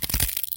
Add fracture sound effects
fracture_2.wav